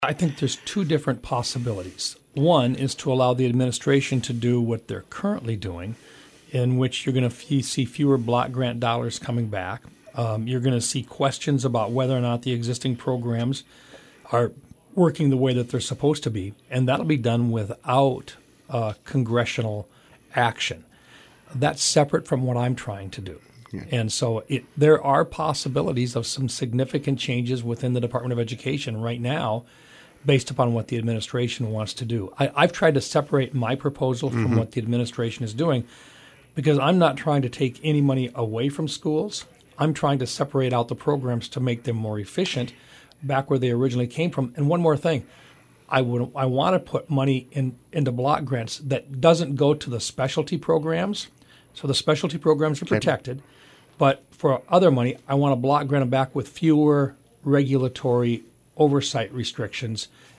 Recap of Senator Mike Rounds’ interview with Hub City Radio